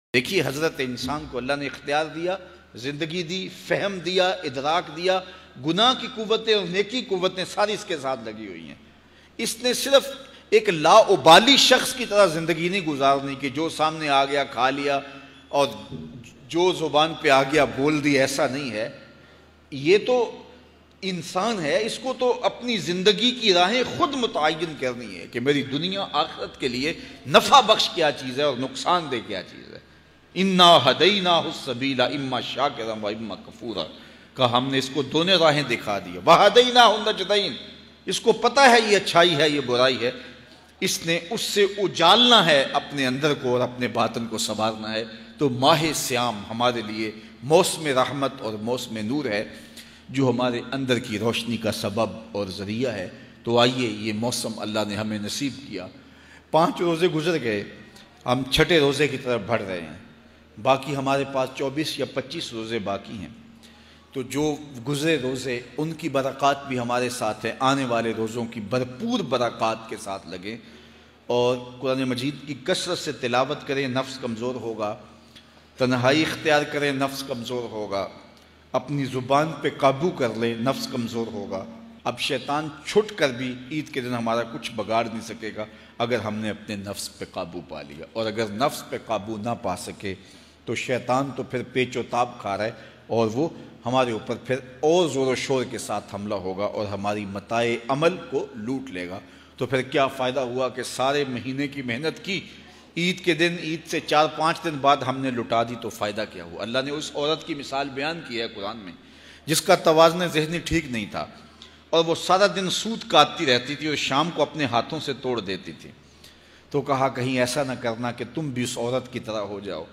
Aik Shart Pr Sari Zindgi K Gunah Maaf New Bayan 2018.mp3